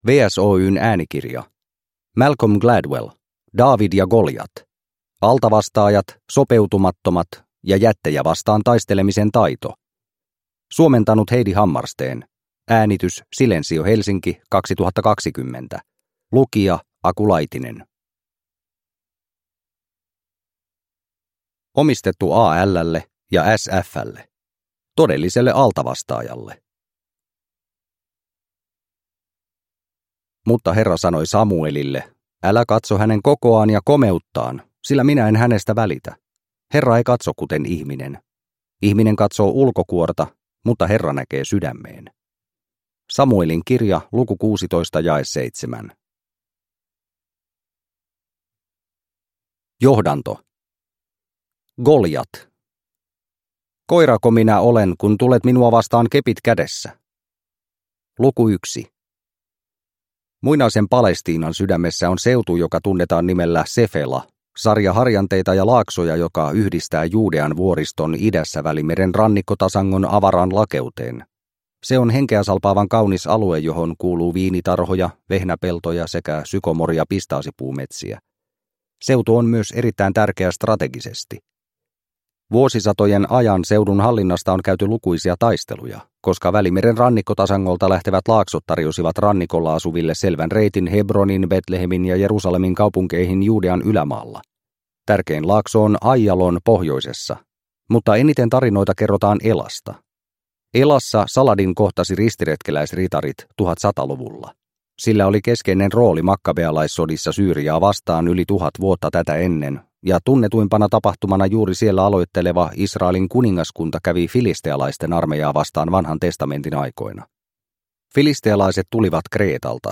Daavid ja Goljat – Ljudbok – Laddas ner